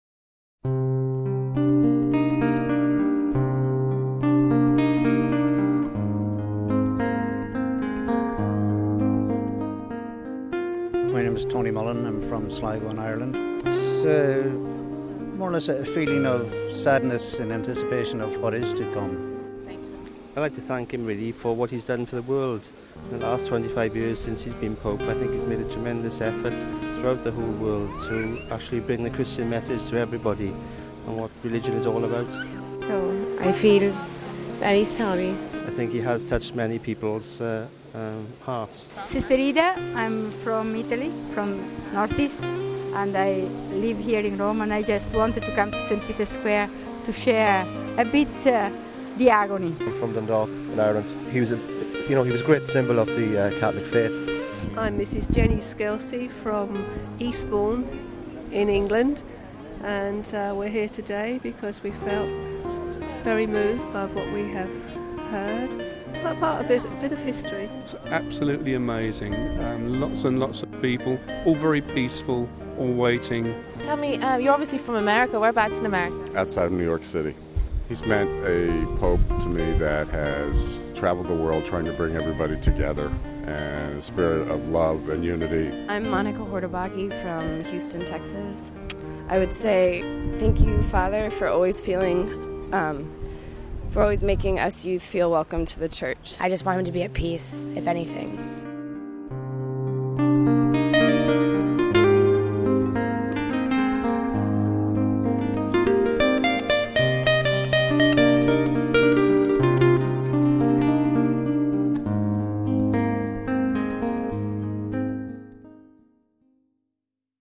Pilgrims prayers
Home Archivio 2005-04-03 10:20:04 Pilgrims prayers We share the atmosphere of St Peter's square with you now, with a small selection of pilgrims' prayers All the contents on this site are copyrighted ©.